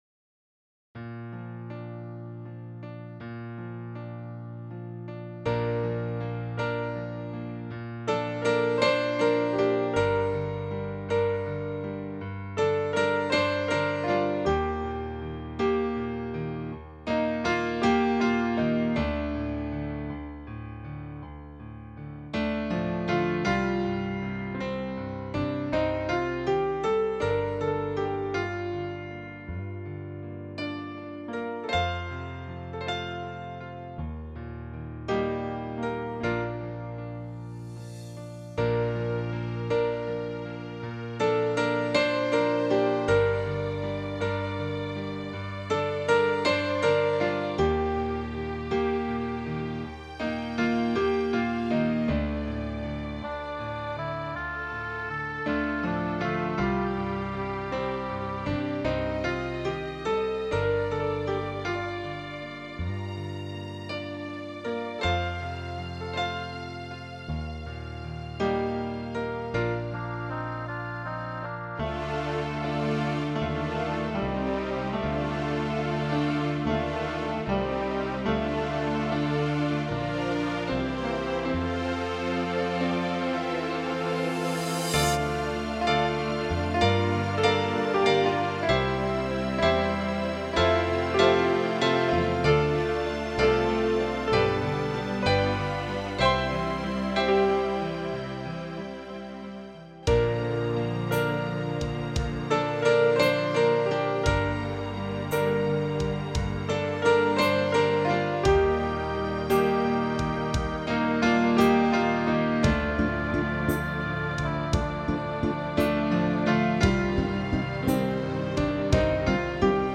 6/  Krásne české skladby
upravené pre hru na piano